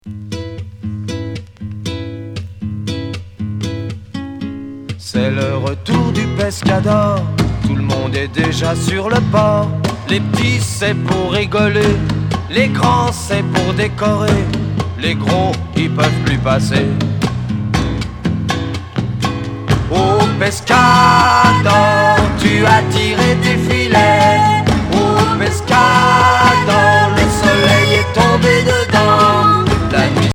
Pop folk Troisième 45t retour à l'accueil